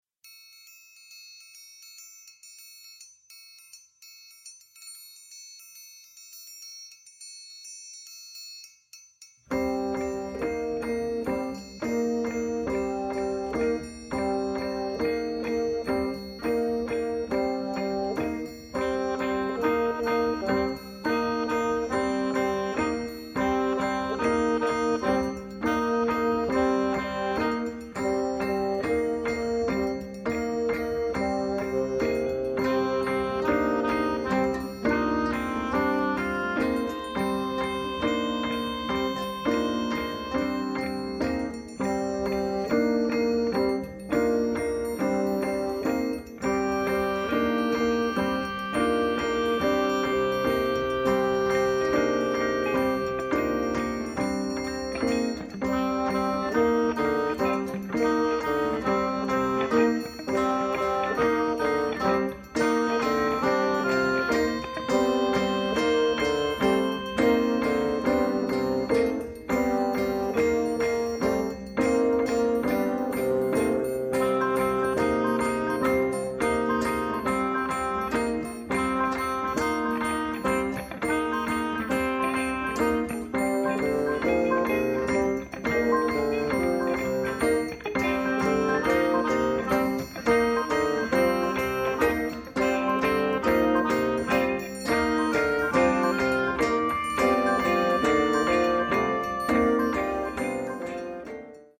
Stereo
oboe
bassoon
guitar
electric guitar
percussion
piano